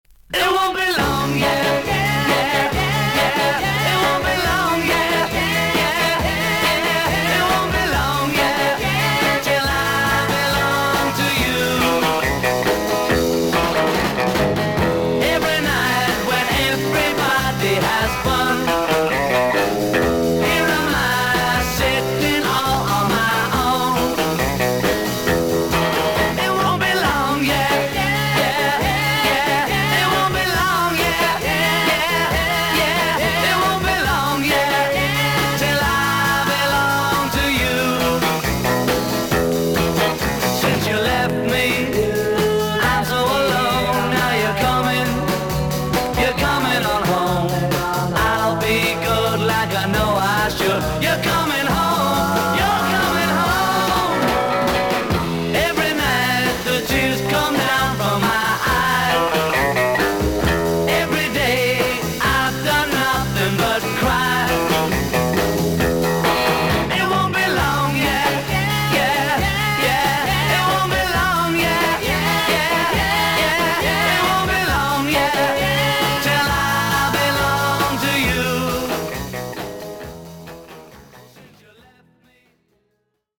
音のグレードはVG++〜VG+:少々軽いパチノイズの箇所あり。少々サーフィス・ノイズあり。クリアな音です。